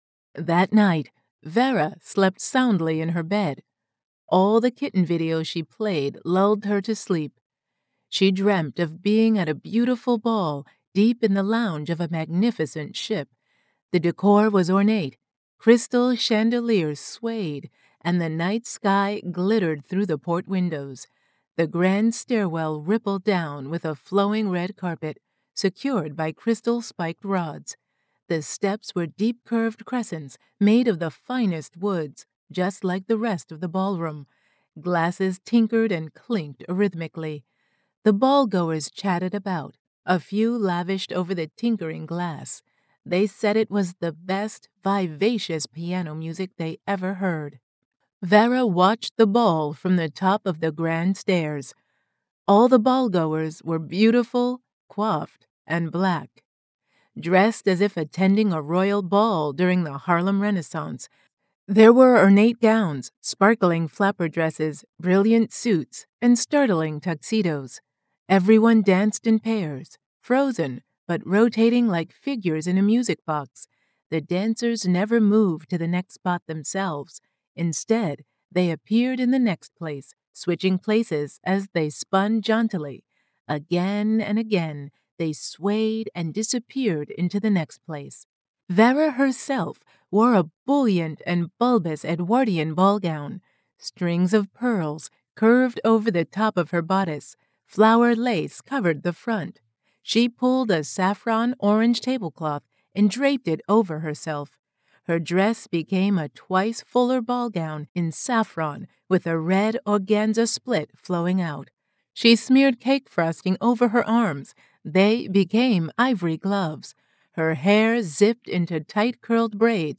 “Dreamer” is now carried at Afrori Books in the UK & the audiobook is now available! Get a free audiobook copy